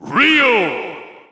The announcer saying Ryu's name in English and Japanese releases of Super Smash Bros. 4 and Super Smash Bros. Ultimate.
Ryu_English_Announcer_SSB4-SSBU.wav